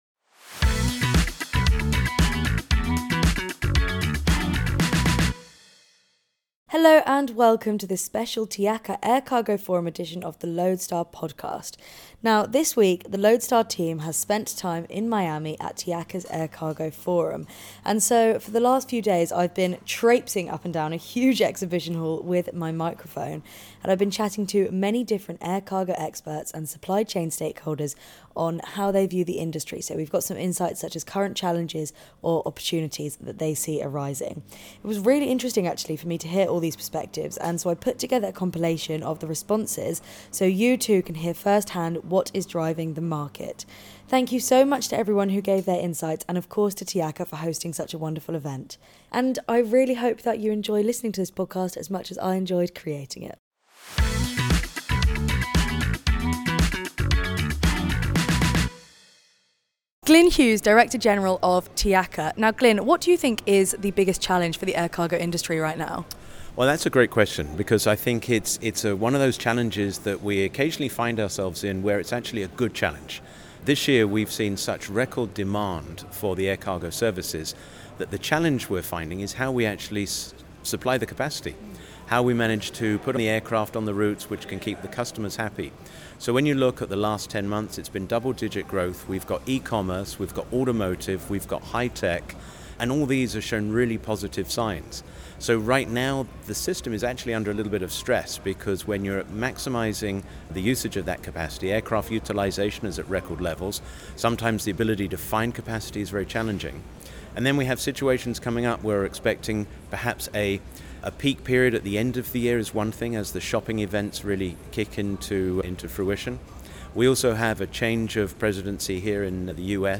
So, not to worry if you were unable to attend the event, this compilation of clips from the trade-show floor will make you feel like you were there in person - in less than 15 minutes!